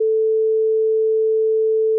Lage toon (440 Hz)
low_tone.wav